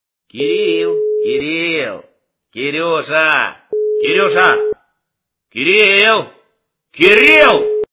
» Звуки » Именные звонки » Именной звонок для Кирила - Кирил, Кирил, Кирюша, Кирюша, Кирил, Кирил
При прослушивании Именной звонок для Кирила - Кирил, Кирил, Кирюша, Кирюша, Кирил, Кирил качество понижено и присутствуют гудки.